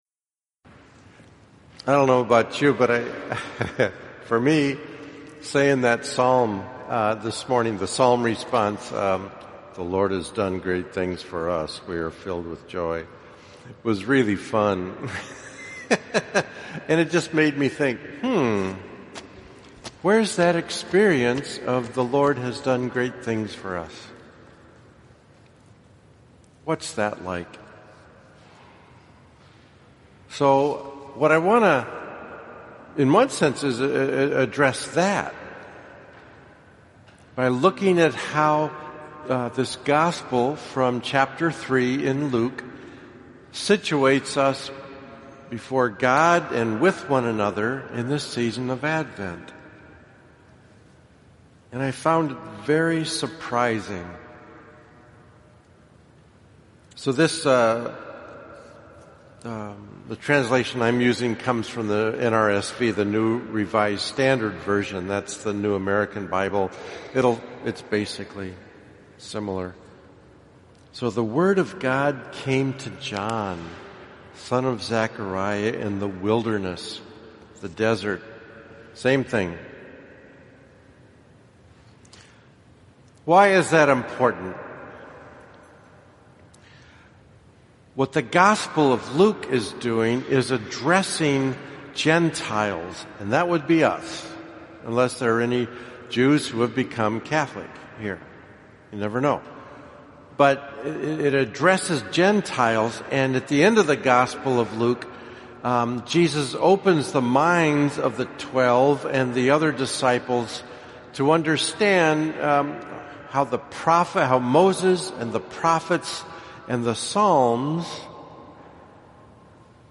This week’s homily got away from me.